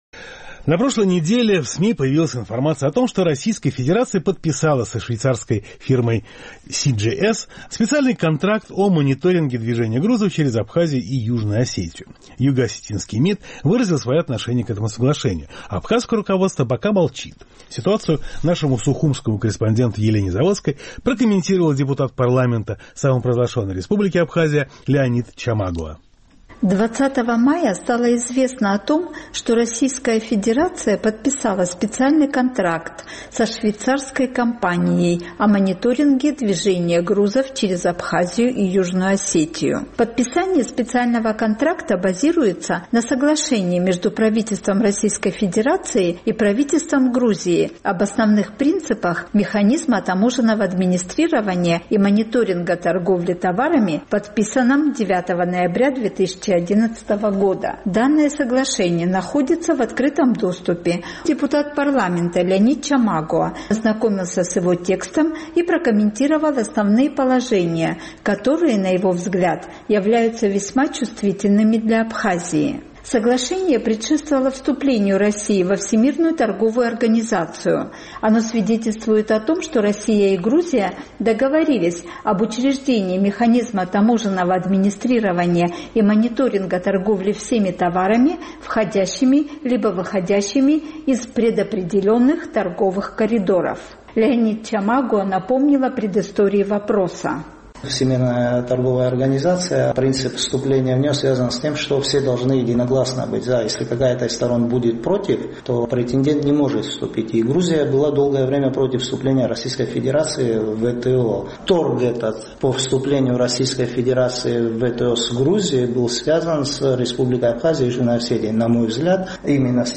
На прошлой неделе в СМИ появилась информация о том, что Российская Федерация подписала со швейцарской организацией специальный контракт о мониторинге движения грузов через Абхазию и Южную Осетию. Ситуацию прокомментировал депутат абхазского парламента Леонид Чамагуа.